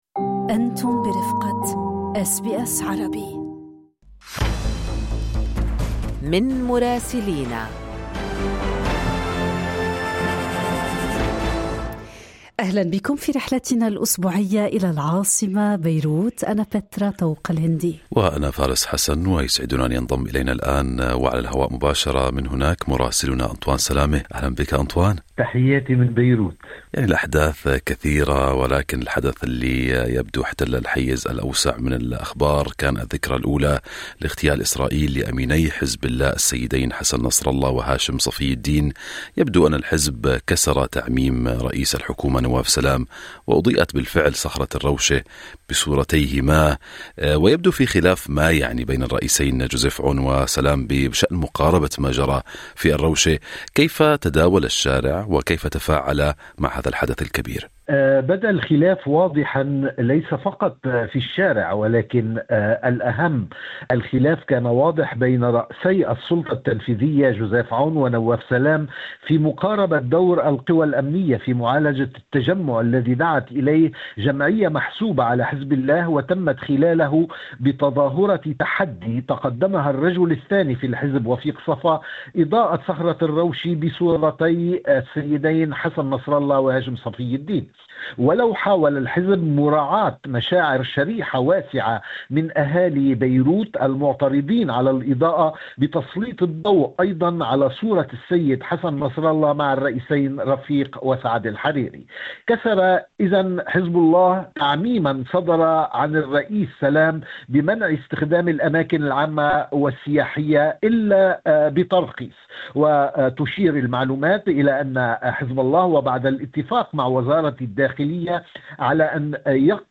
توالت التطورات السياسية والأمنية في لبنان خلال الأيام الماضية، بالتزامن مع إحياء حزب الله الذكرى الأولى لاغتيال القياديين حسن نصرالله وهاشم صفي الدين، في ظل توتر سياسي متزايد بين الحكومة والحزب. المزيد مع مراسلنا في بيروت